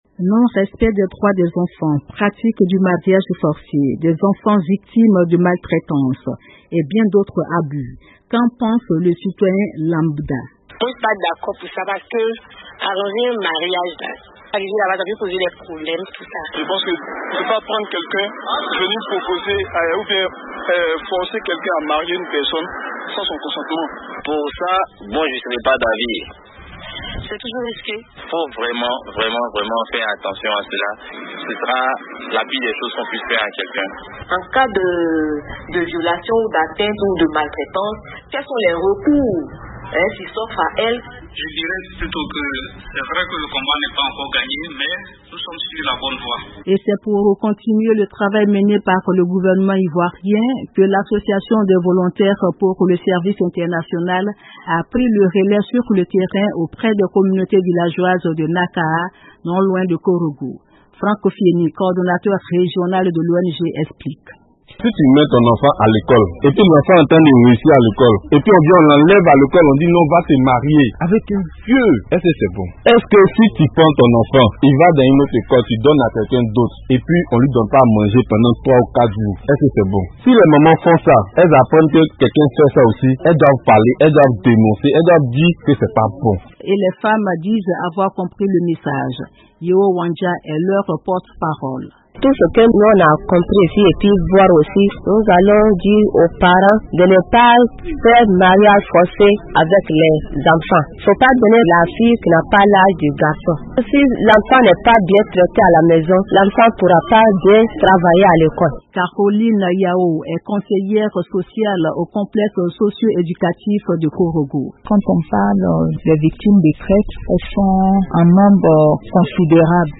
En Côte d’Ivoire, l’Association des volontaires pour le service international a organisé une journée de sensibilisation sur le mariage forcé, les maltraitances et autres types d’abus subis par les enfants. Le reportage